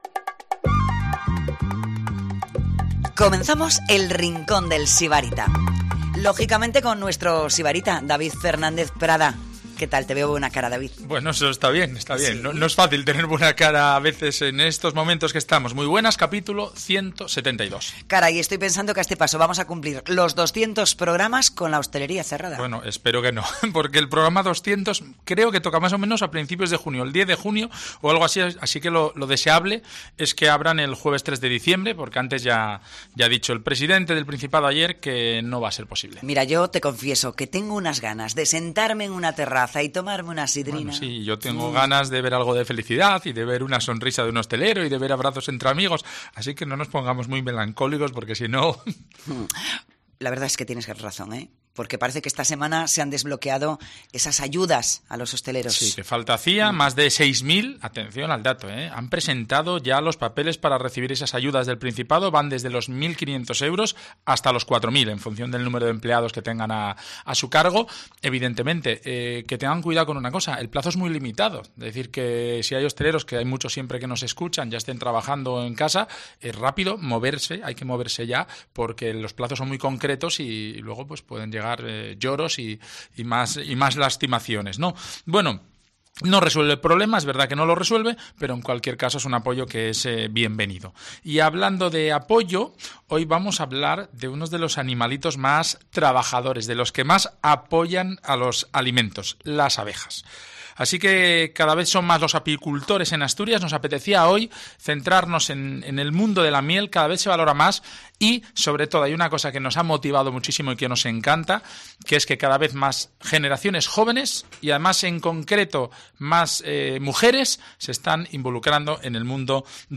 La miel asturiana se hace fuerte, y cada vez más eco. Conversamos con productores que consideran que no se le da la suficiente importancia que tiene al sector.